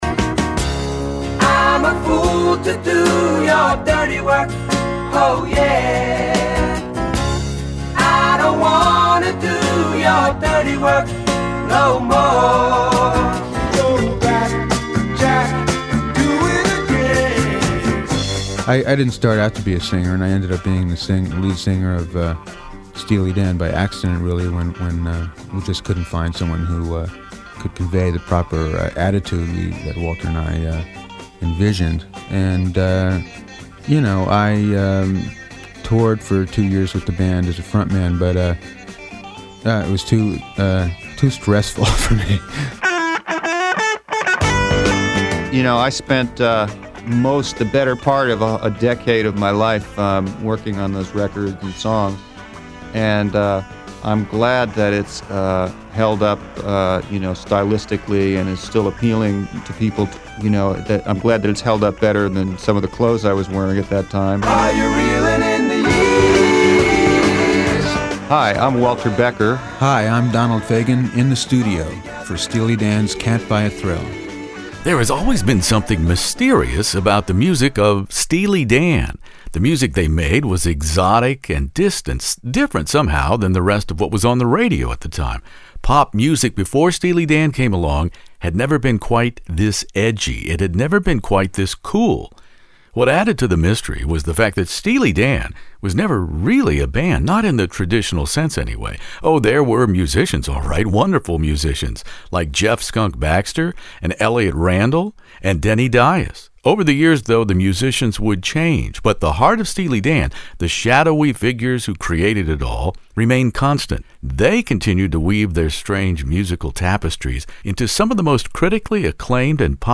Steely Dan duo singer/keyboard player Donald Fagen and the late guitarist Walter Becker recall their humble beginnings at Bard College, then being stranded a continent away in Los Angeles expected to write pop hits for others, before concluding that no one else could interpret their quirky, dark sense of lyrical humor with jazz-informed arrangements.( The late Walter Becker (L) with Donald Fagen of Steely Dan )